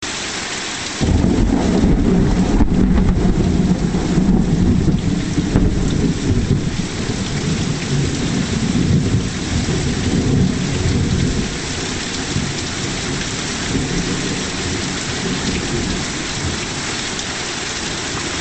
Index of /informatyka/8_Tworzymy/Swiat dzwiekow i obrazow/Burza/
Thunder.mp3